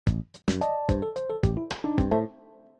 Index of /phonetones/unzipped/LG/KU310/Default sounds
Power Off.aac